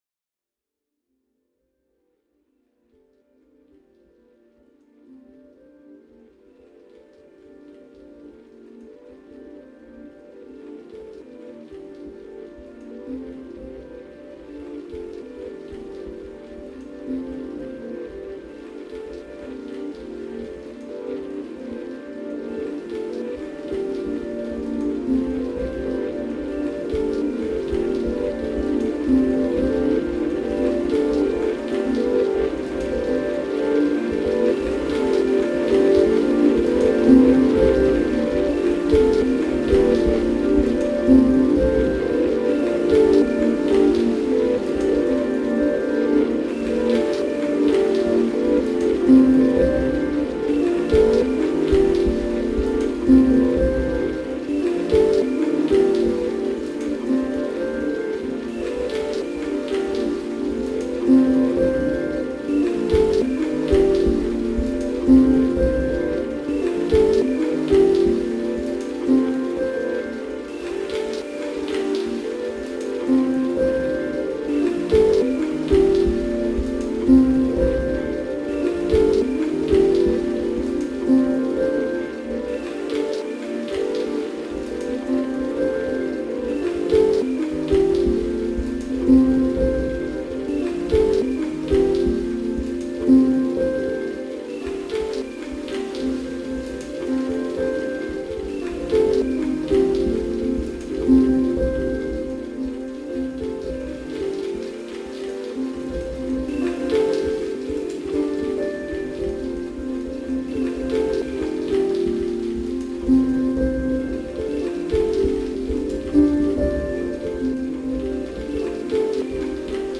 Isha call to prayer in Marrakesh square reimagined